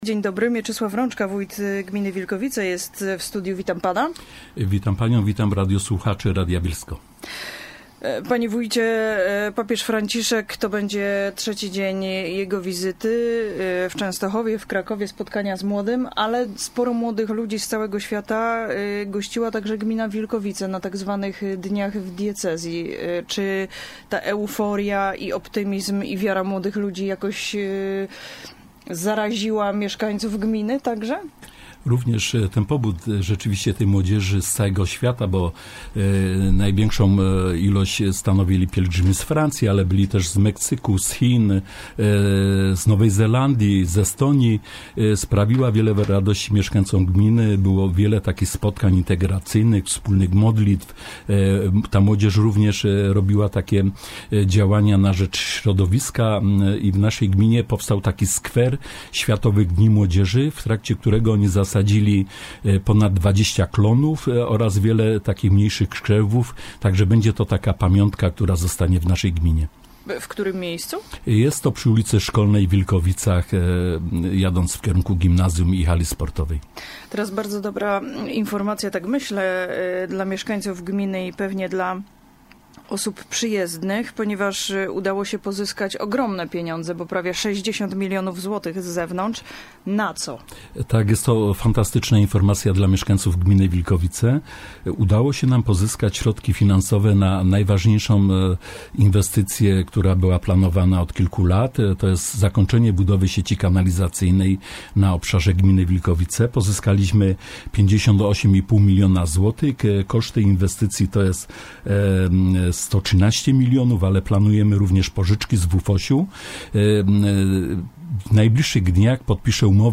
Aktualności - WYWIAD W RADIU BIELSKU Z WÓJTEM MIECZYSŁAWEM RĄCZKĄ